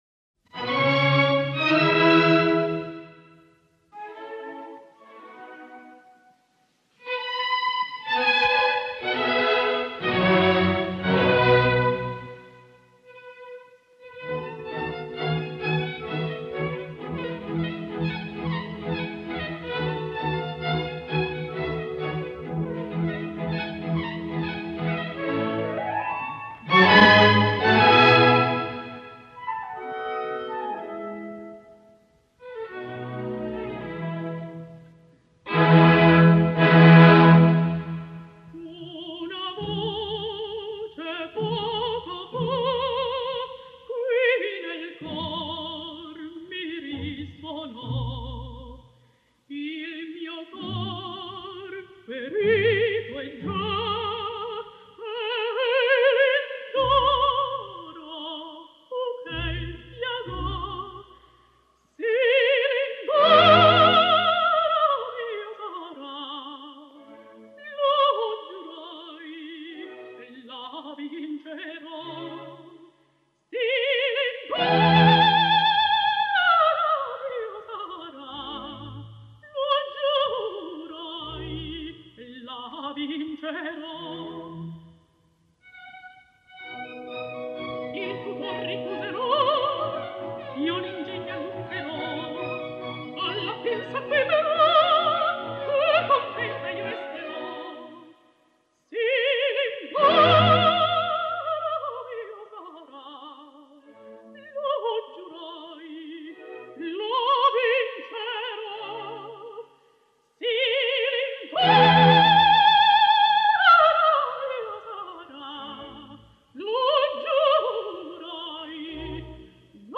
La gravació és del 10 d’octubre de 1950, provinent d’un recital de la Cetra.